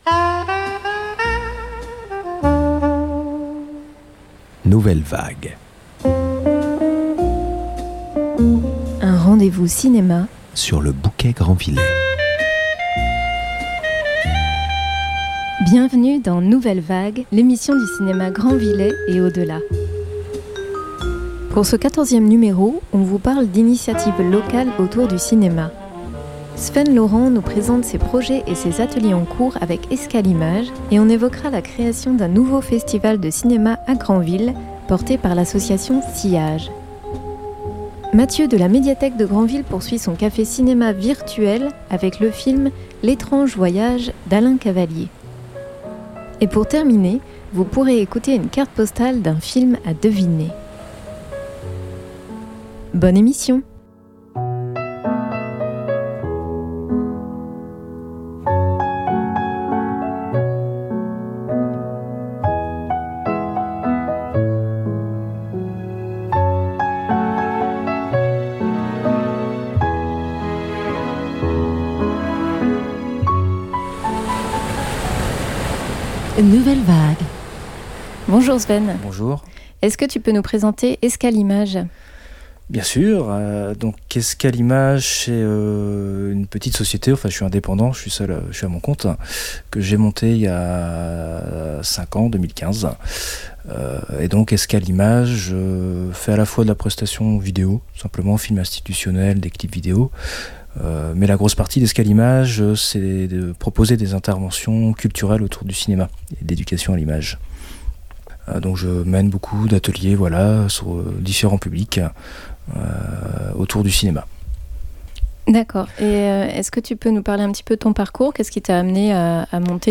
Les extraits de musiques de films qui ponctuent l’émission
Animatrices radio